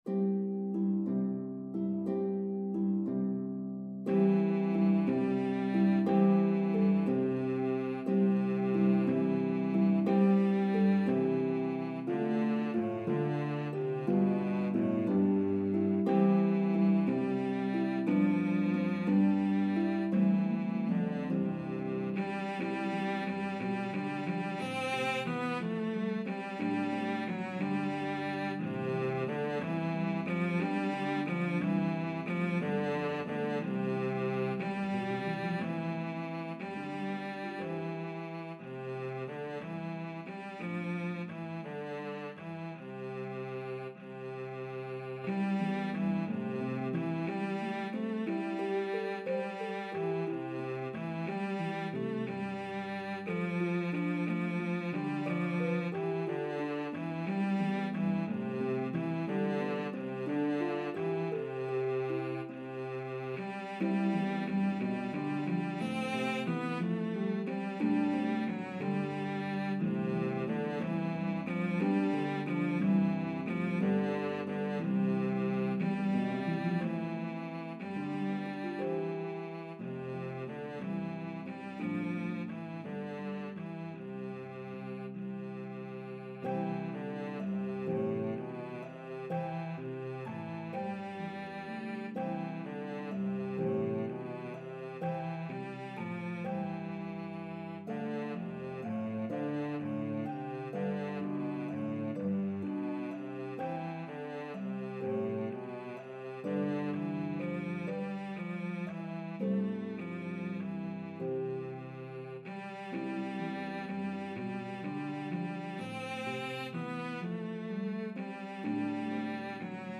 traditional Christmas hymn
Harp and Cello version